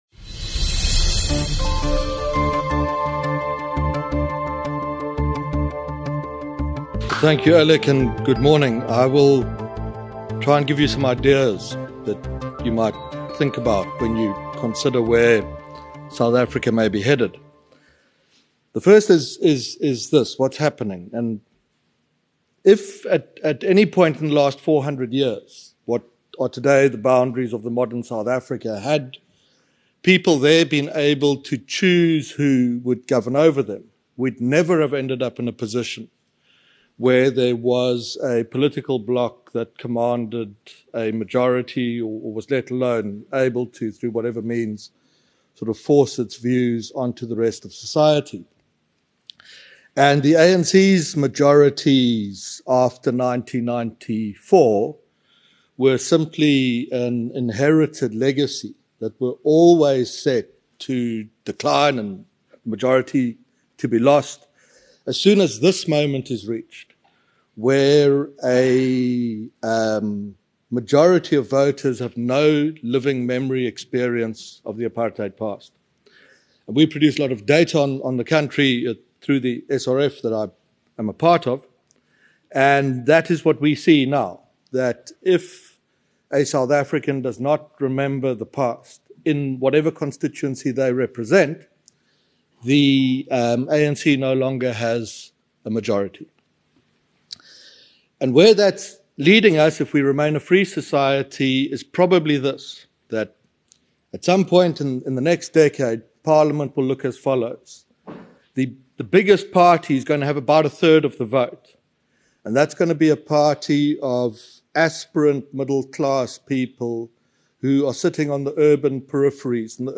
delivered a captivating keynote speech at the BizNews Conference in London